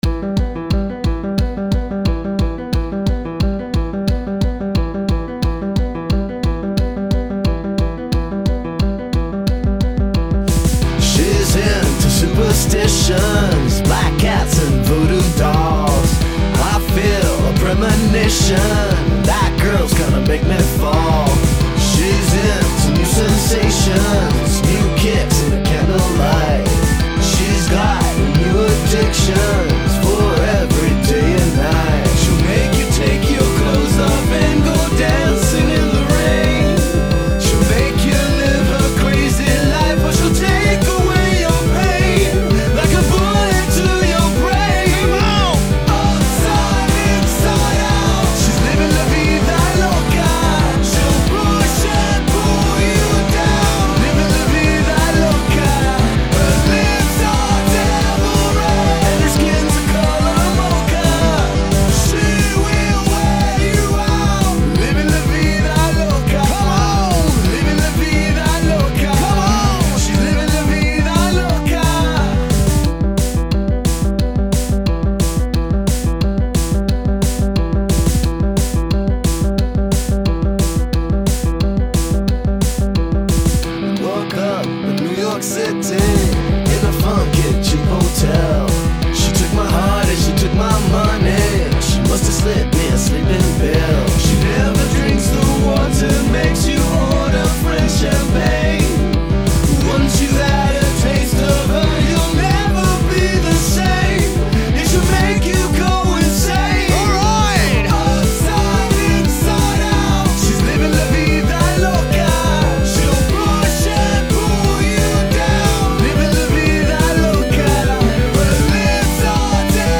Game Music
guitar synth